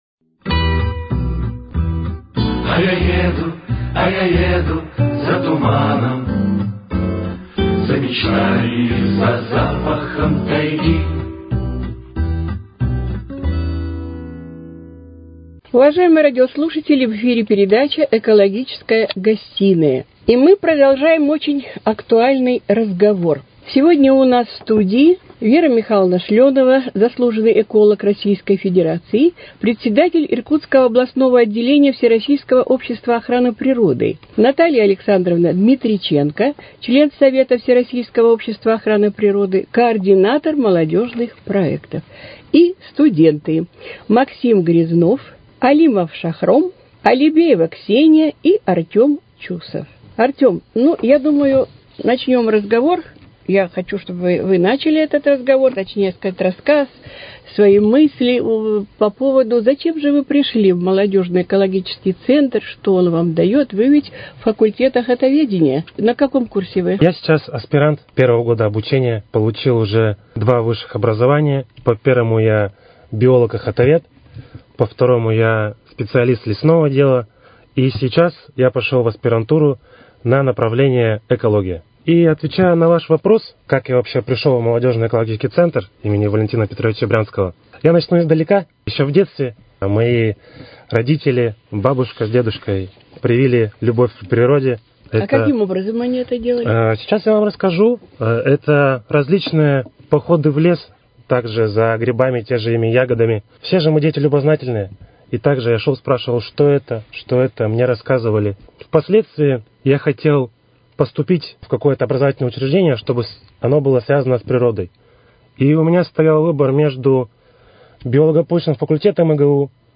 Гостями экологической гостиной на сей раз стали студенты из разных вузов, которые являются членами Молодежного экологического центра имени Валентина Петровича Брянского.